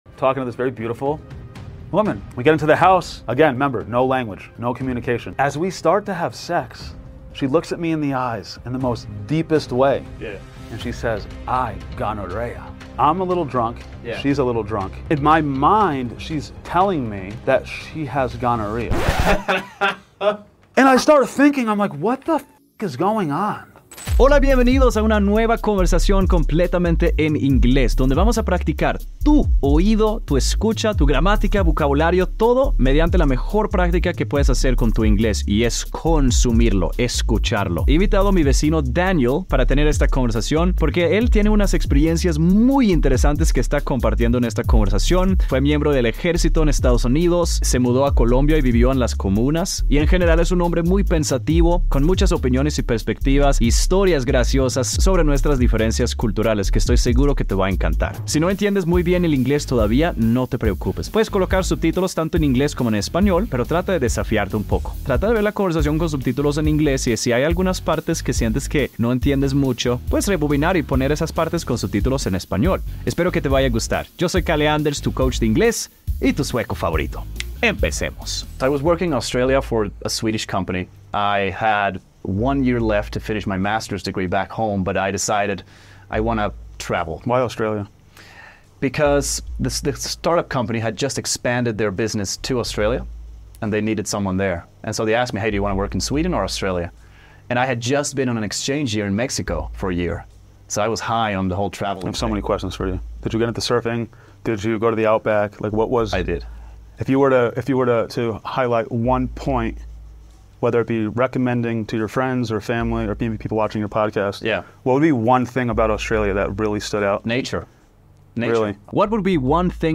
Escucha esta conversación y empieza a hablar inglés en TIEMPO RÉCORD